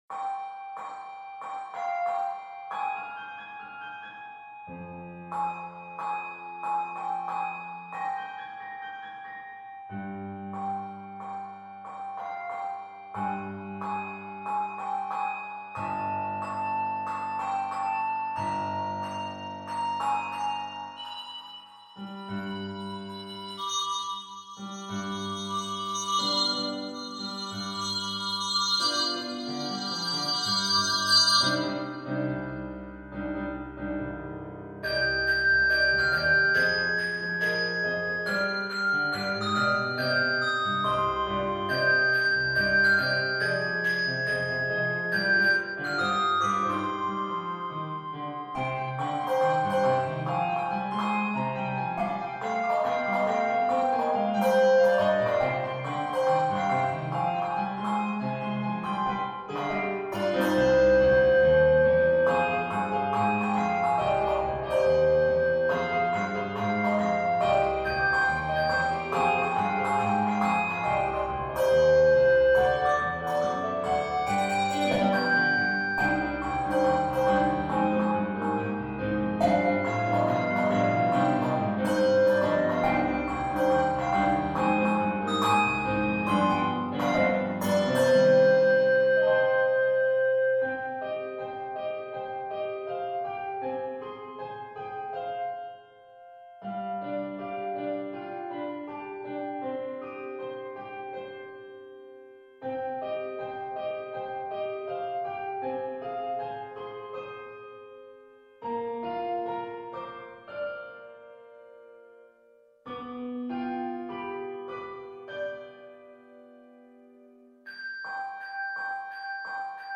handbell soloist